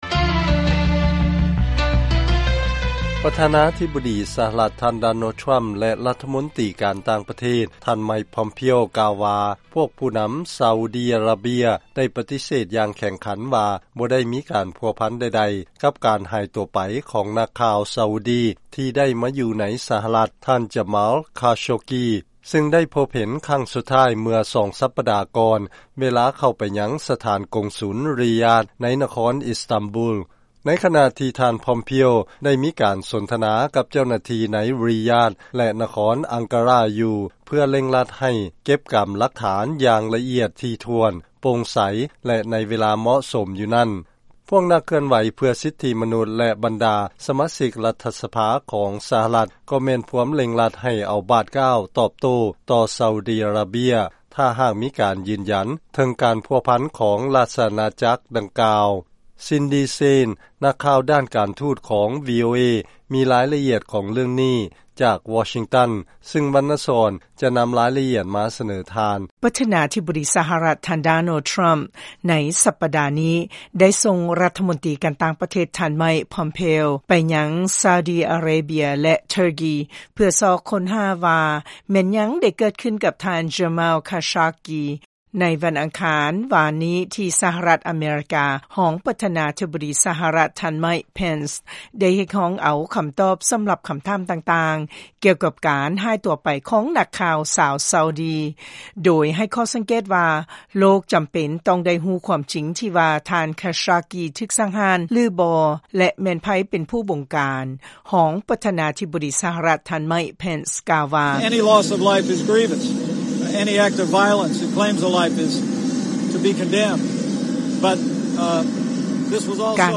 ລາຍງານກ່ຽວກັບການຫາຍຕົວໄປຂອງນັກຂ່າວຊາວຊາອຸດີ